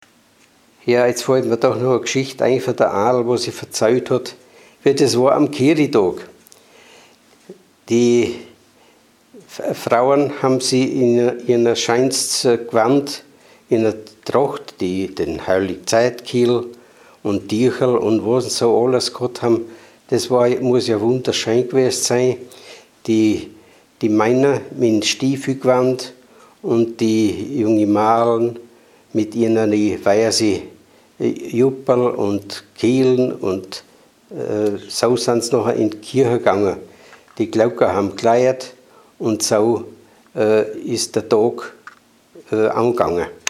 Mundart: Wuderscher Dialekt
Man hörte dabei eine gedehnte „ua“ Aussprache.
Unser Budaörser Heimatmuseum besitzt eine Sammlung von Tonaufnahmen in wunderbar gesprochenem Wuderscher Dialekt, den wir in einer Hörprobe präsentieren: